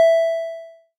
airport alamr alarm atention chimes ecuador public-anoucement quito sound effect free sound royalty free Sound Effects